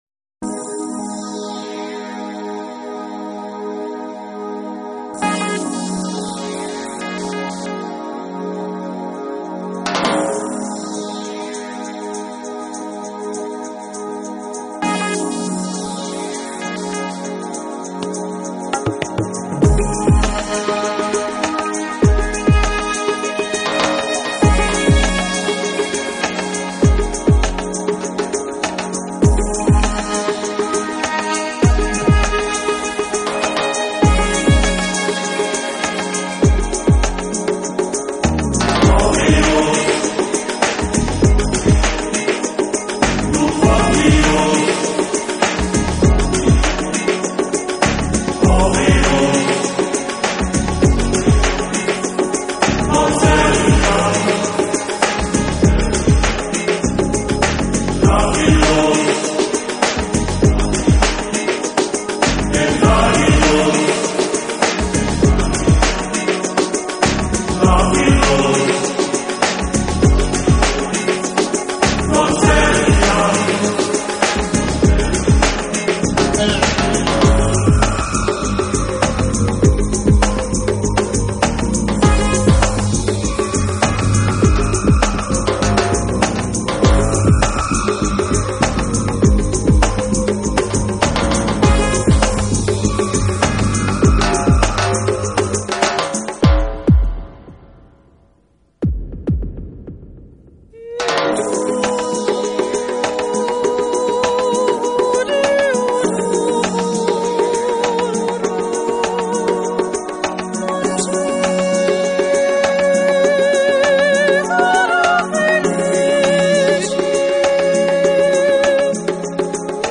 专辑类型：New Age